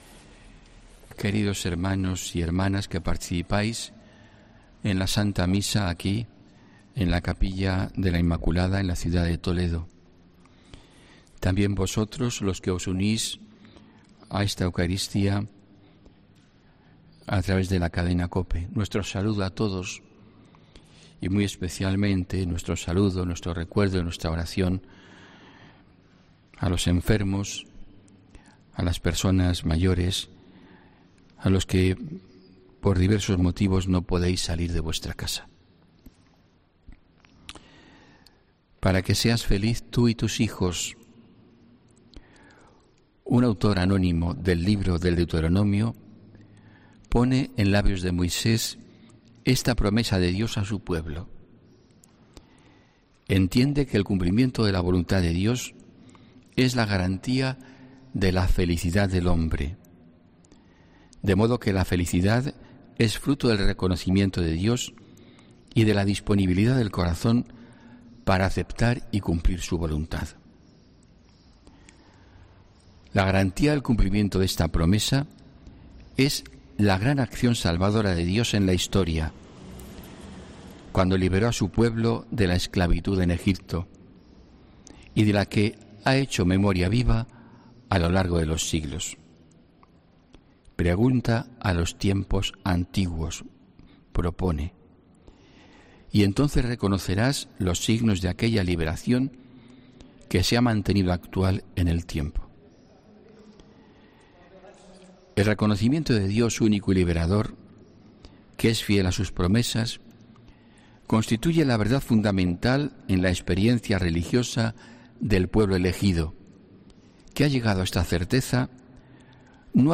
HOMILÍA 30 MAYO 2021